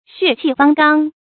注音：ㄒㄩㄝˋ ㄑㄧˋ ㄈㄤ ㄍㄤ
血氣方剛的讀法